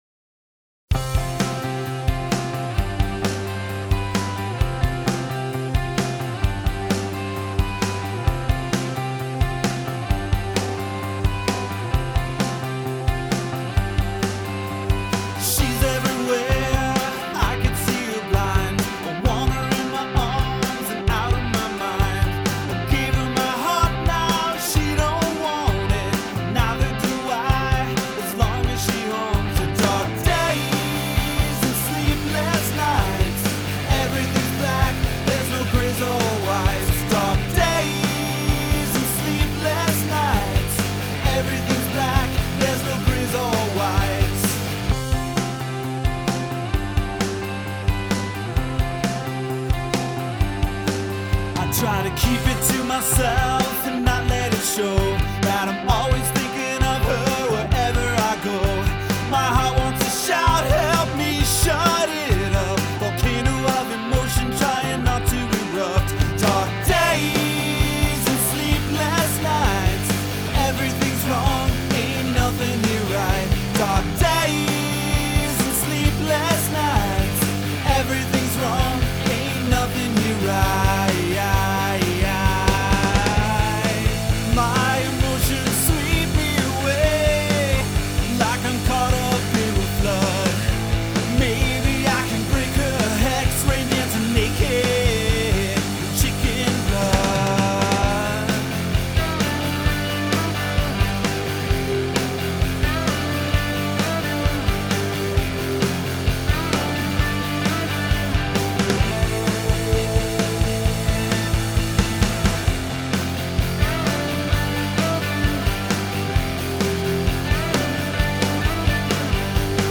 These are our demos — we recorded it all ourselves at home.